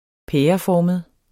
pæreformet adjektiv Bøjning -, ..formede Udtale [ -ˌfɒˀməð ] Betydninger 1. af form som en pære Blodet havde samlet sig i en pæreformet dråbe ved roden af pegefingeren PoØrum89 Poul Ørum: Dagens lys.